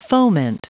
Prononciation du mot foment en anglais (fichier audio)
Prononciation du mot : foment